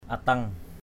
/a-tʌŋ/ (d.) đế (cỏ lợp) = chaume. thatch.